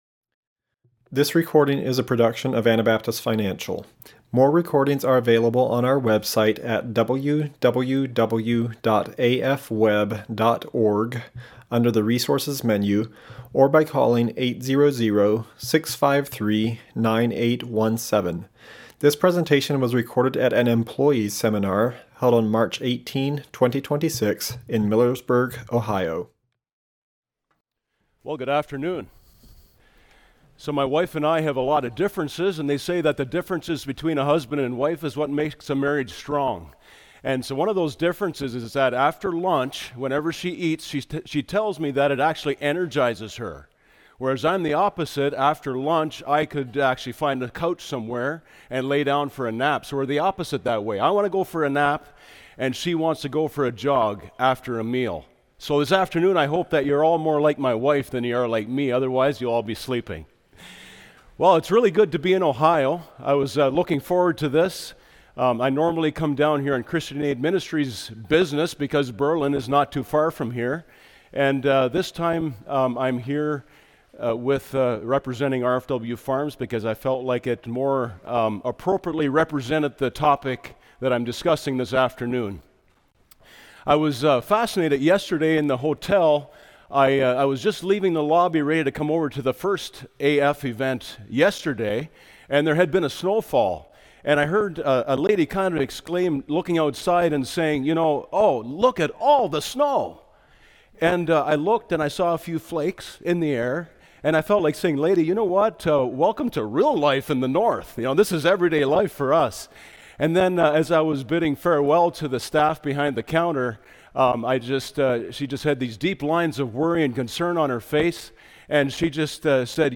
Ohio Employee Seminar 2026